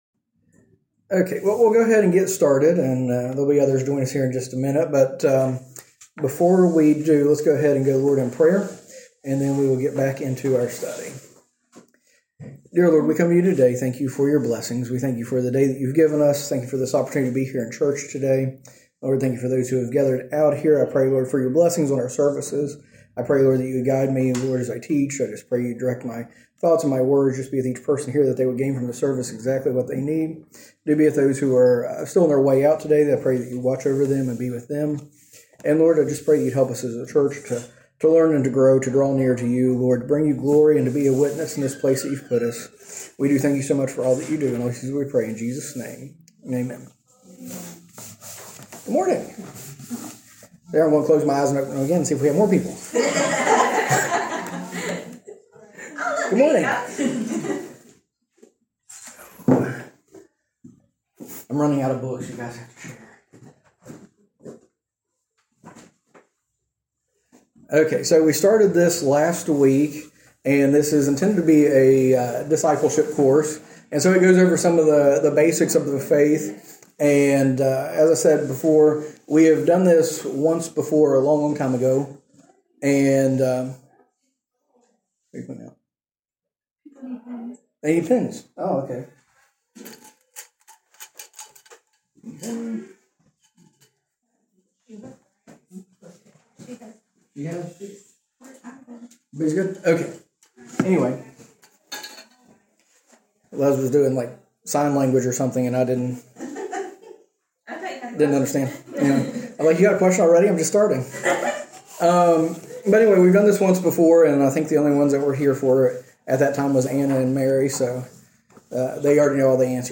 A message from the series "Continue."